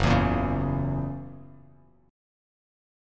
F#7 Chord
Listen to F#7 strummed